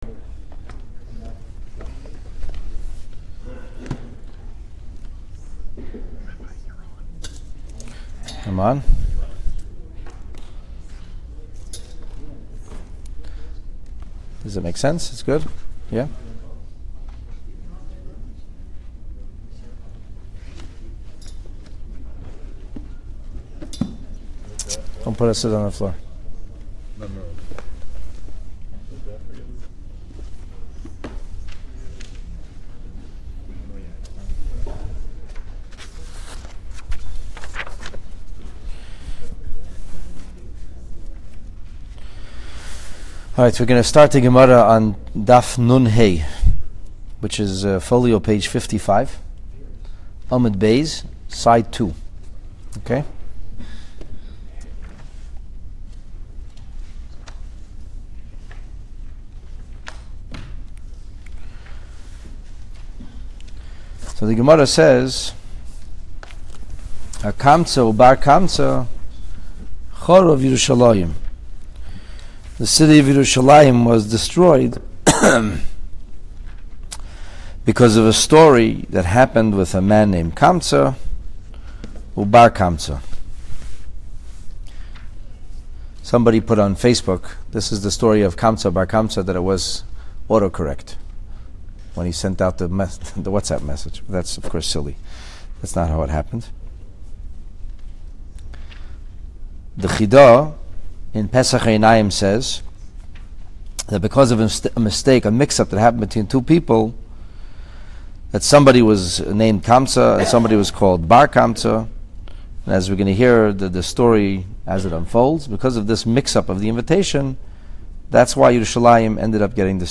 Part One: Talmud class about the Churban from Perek Hanizakin. Talmud Gittin 55b-56a